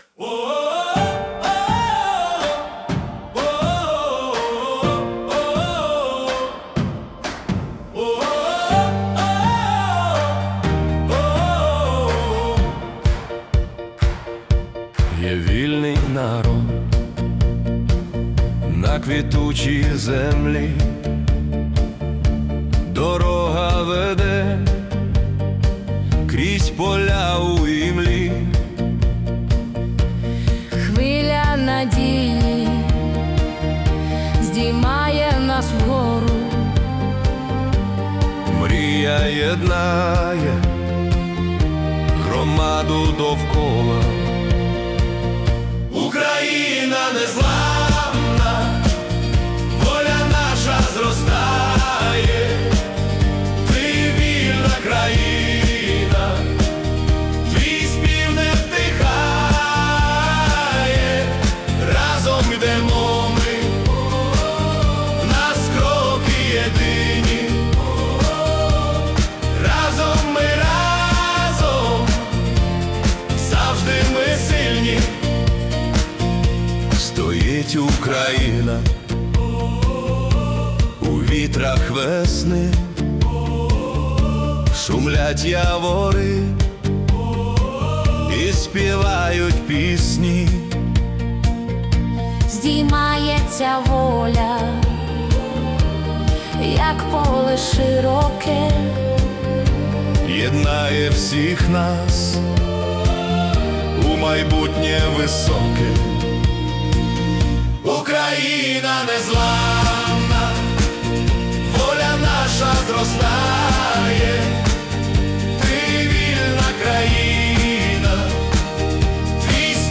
Ukrainian Pop / Disco-Pop Anthem